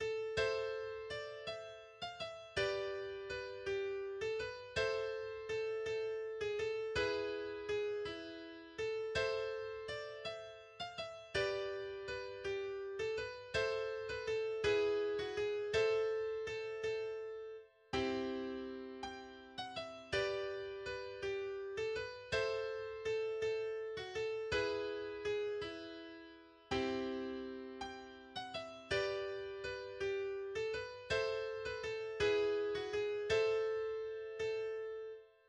[[Kategorie:Volkslieder]]
[[Kategorie:englische Lieder]]